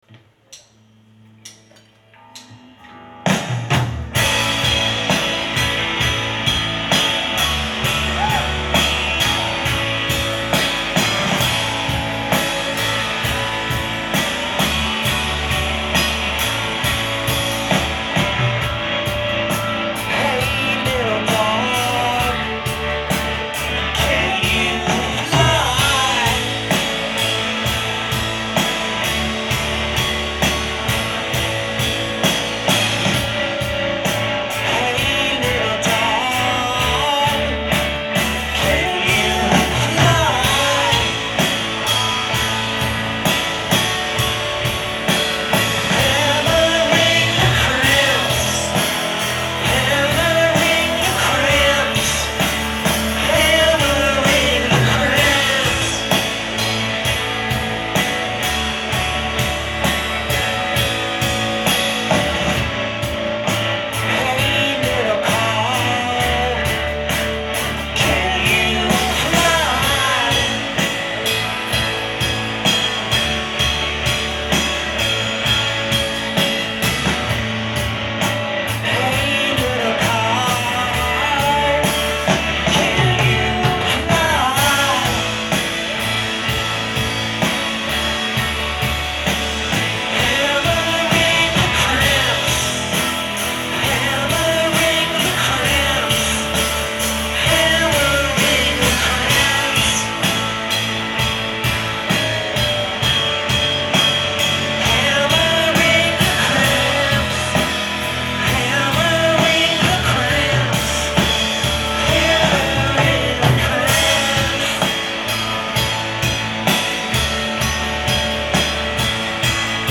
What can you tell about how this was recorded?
Live at The Paradise in Boston, Massaschuetts